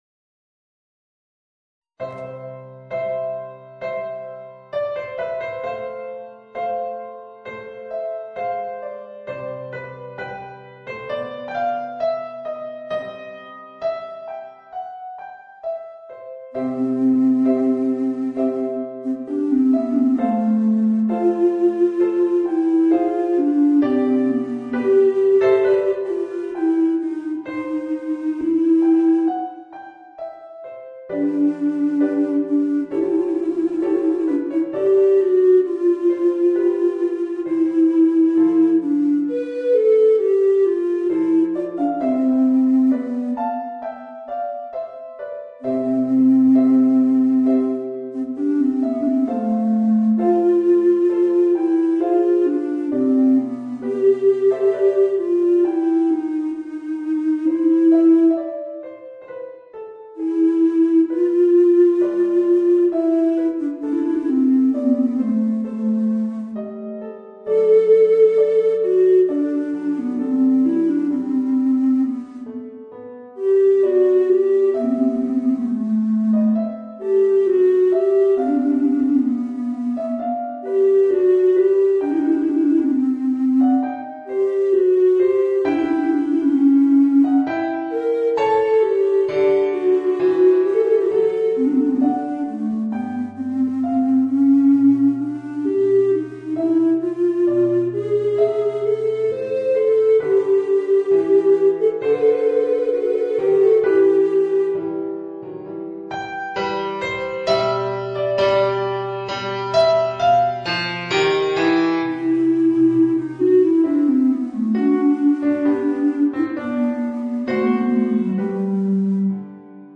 Voicing: Bass Recorder and Organ